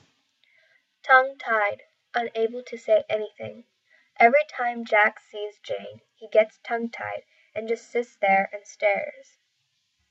英語ネイティブによる発音はは下記のリンクをクリックしてください。
Tongue-tied.mp3